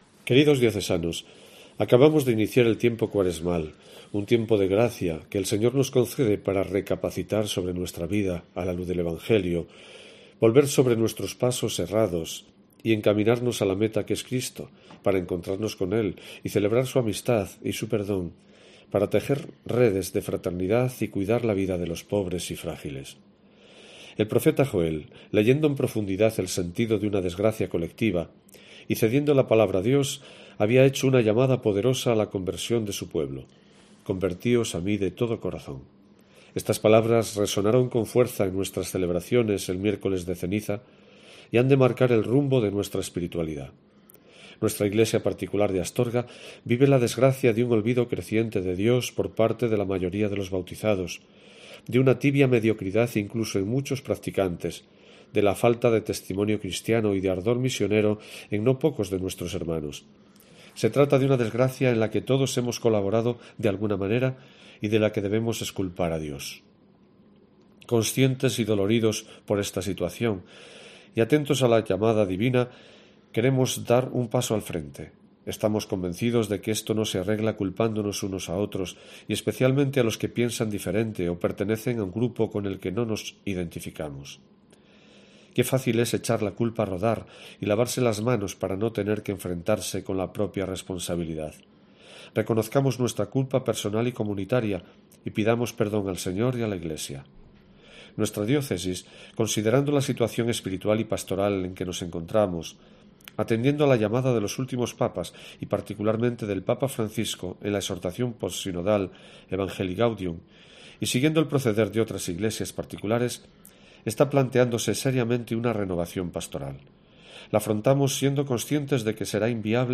AUDIO: Escucha aquí la carta de esta semana del obispo de Astorga